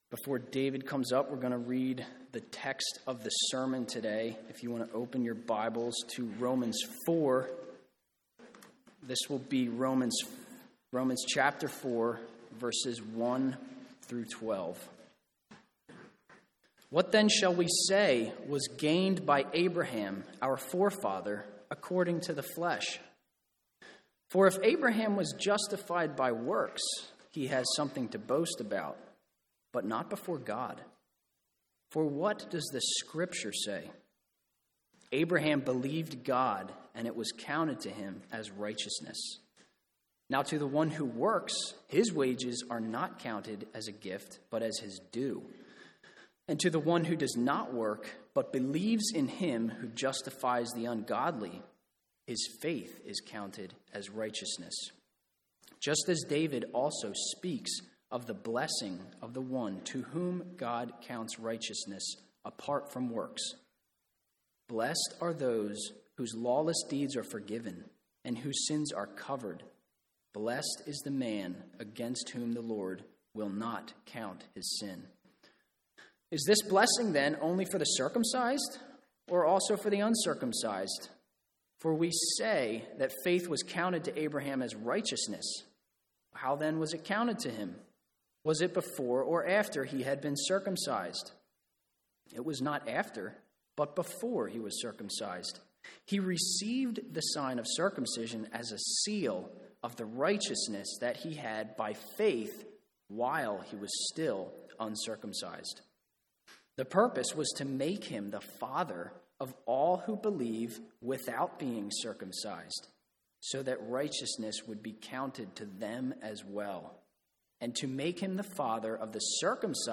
Romans 4:1-12 Sermon Notes What then shall we say was gained by Abraham